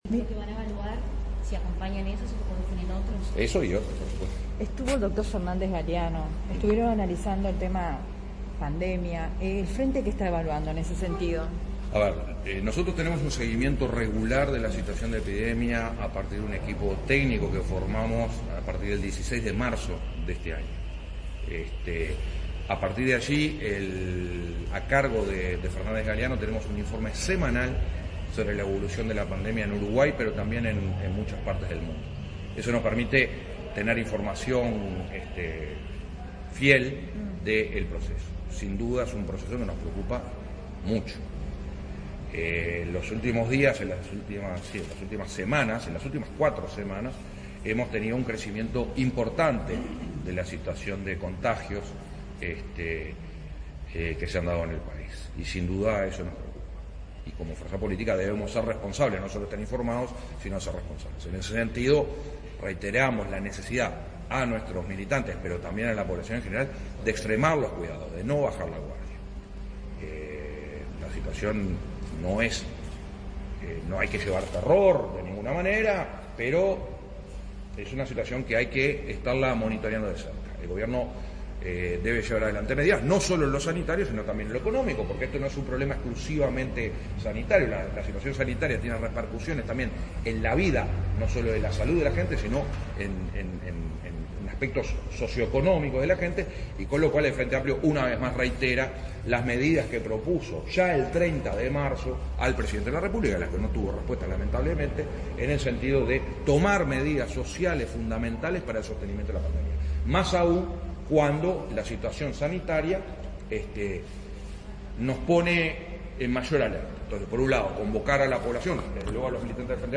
Finalizada la Mesa Política del 13 de noviembre, el presidente del Frente Amplio, Javier Miranda,  se refirió a la pandemia que atraviesa nuestro país y el mundo,  indicando los procedimientos que sigue la fuerza política desde el mes de marzo.